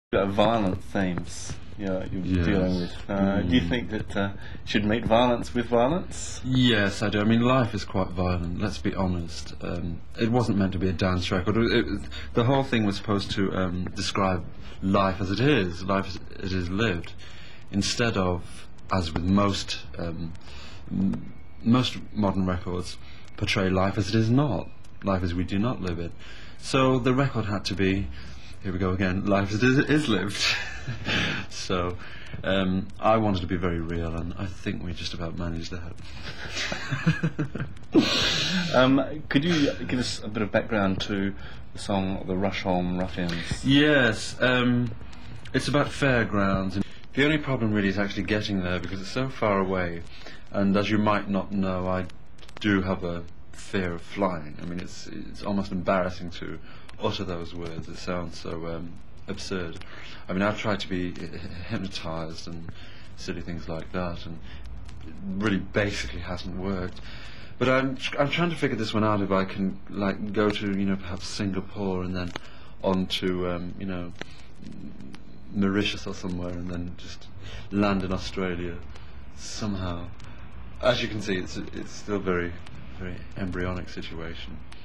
Limited Edition Interview Picture Disc